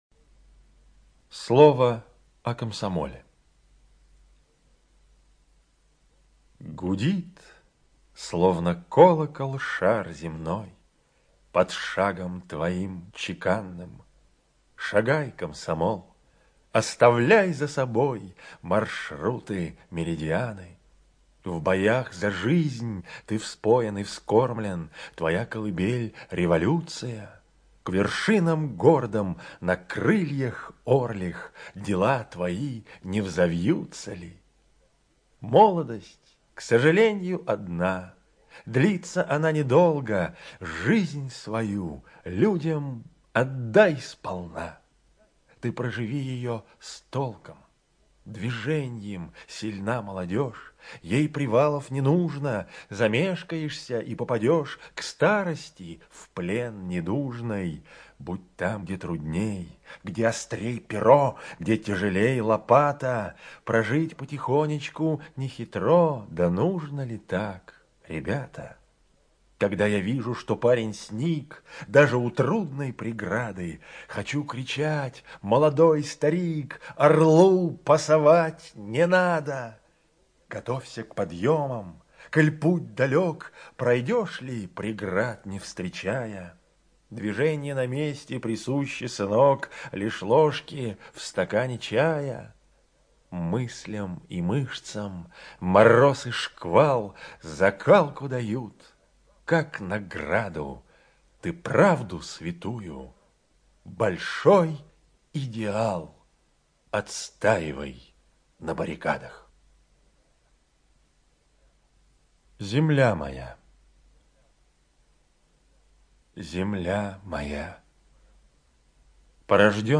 ЧитаетКиндинов Е.
ЖанрПоэзия